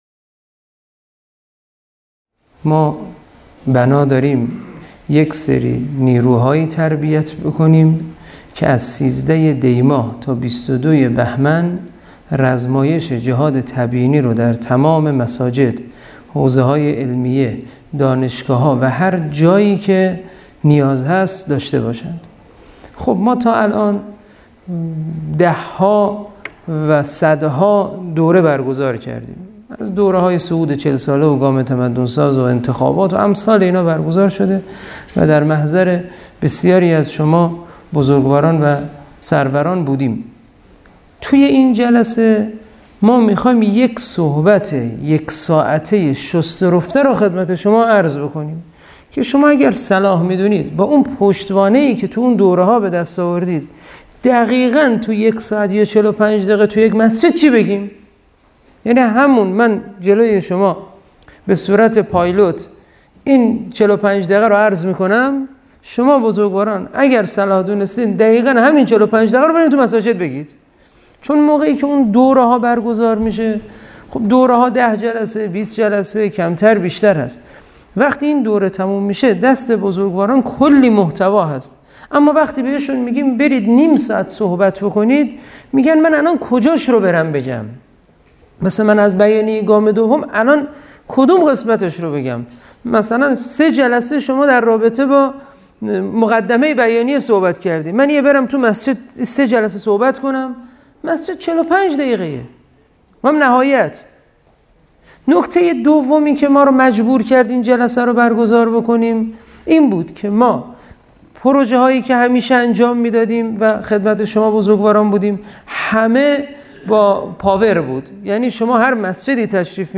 سخنرانی
در کارگاه توانمندسازی مبلغان و سخنرانان تبیین مکتب حاج قاسم سلیمانی